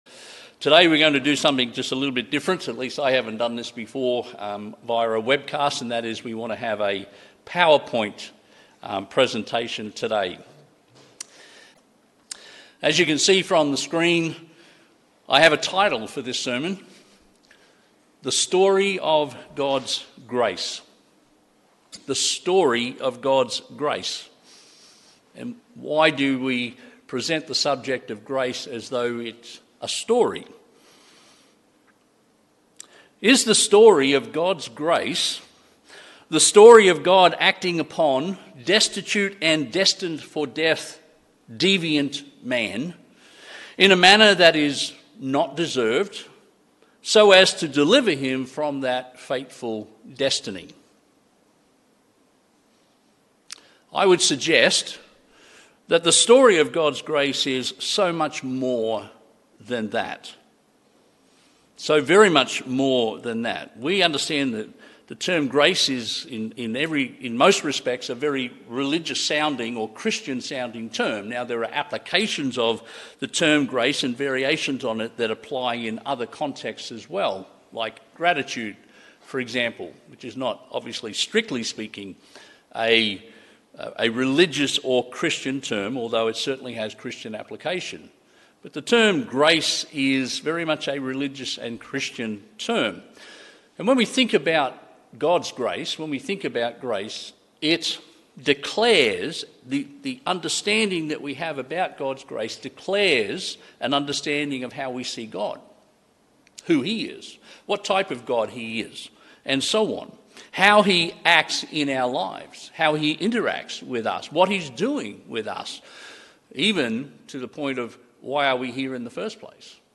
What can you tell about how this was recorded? Given in Melbourne East